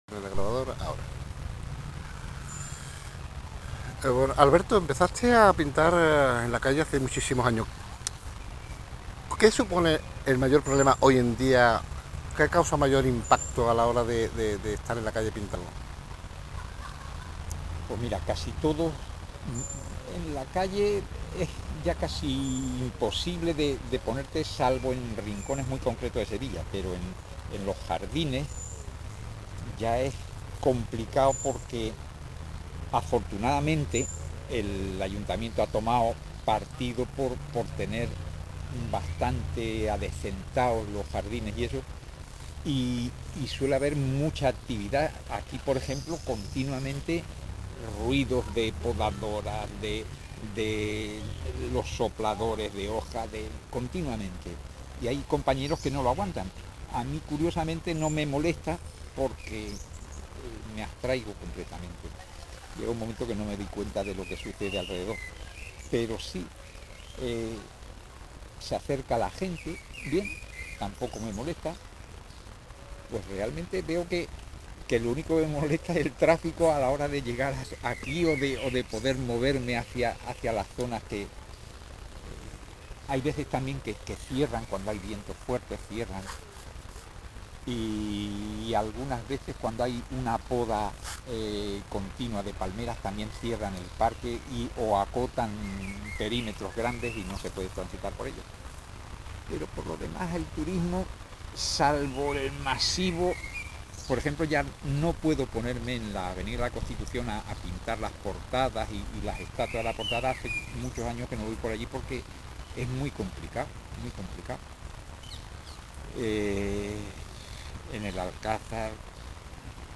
Audio  entrevista                                    Transcripción entrevista